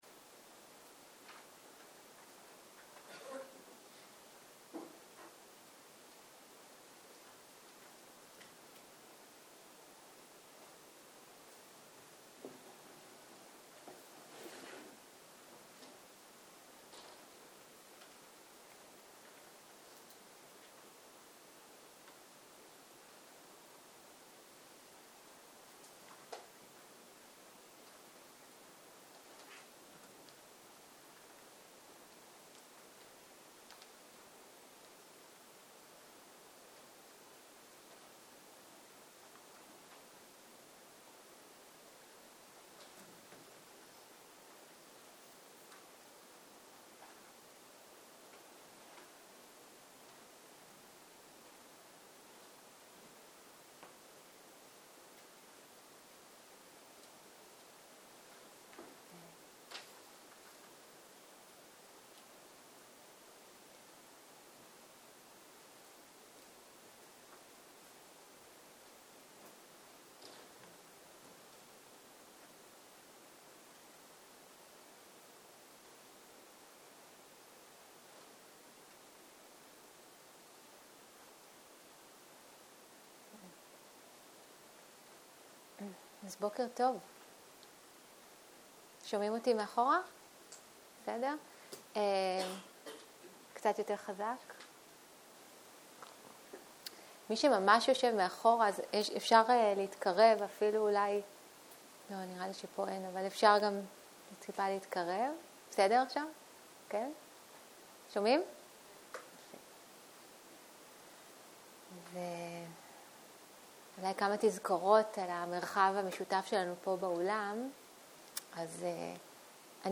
בוקר - הנחיות מדיטציה - הדרשה לביסוס תשומת הלב והדרשה על הבסיסים + הנחיות להליכה 0:00 0:00 סוג ההקלטה: Dharma type: Guided meditation שפת ההקלטה: Dharma talk language: Hebrew